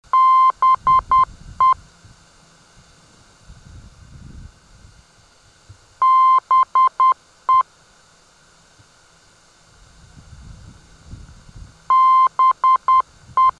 BE - Benton Harbour heard on 397 kHz: (213 kb)